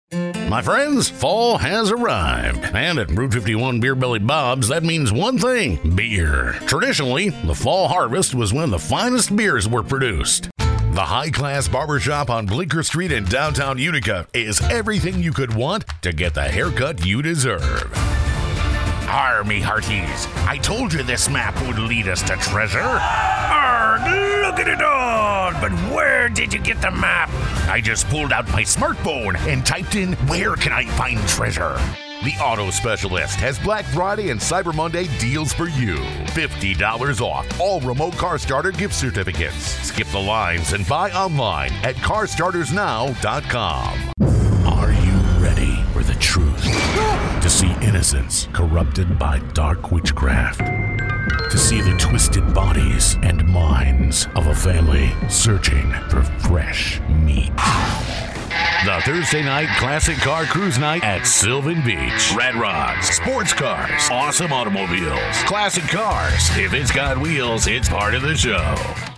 Baritone – Swap A Spot